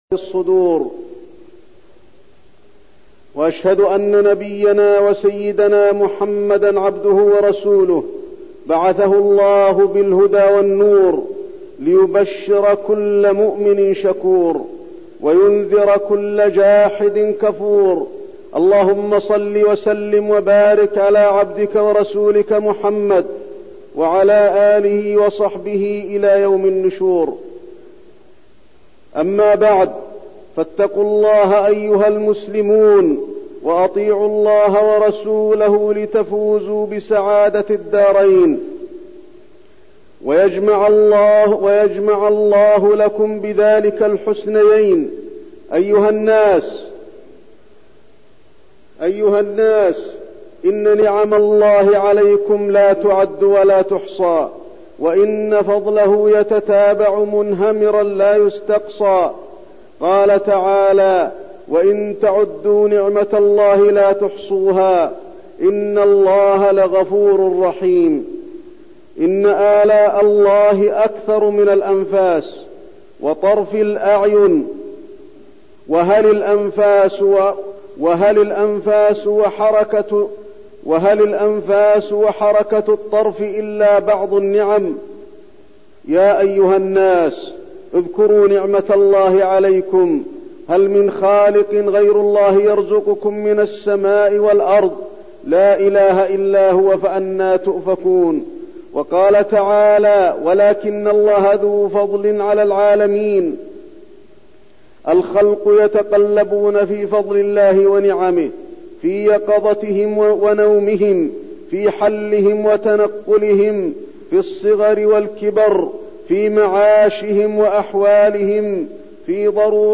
تاريخ النشر ٣٠ جمادى الأولى ١٤١٢ هـ المكان: المسجد النبوي الشيخ: فضيلة الشيخ د. علي بن عبدالرحمن الحذيفي فضيلة الشيخ د. علي بن عبدالرحمن الحذيفي نعم الله تعالى